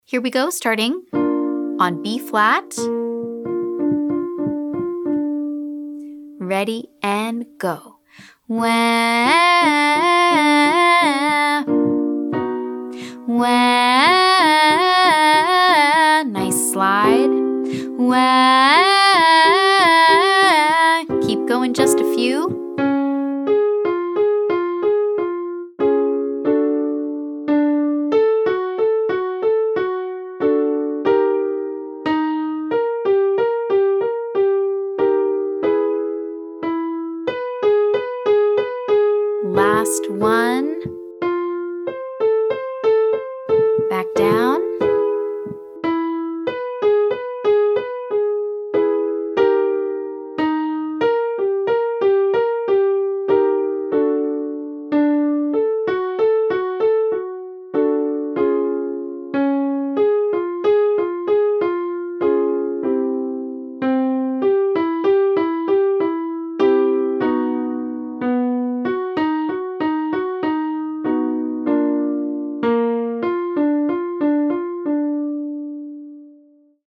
Day 11: Chest-dominant Mix
This is our chest-dominant mix, the mode we typically use for belting.